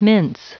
Prononciation du mot mince en anglais (fichier audio)
Prononciation du mot : mince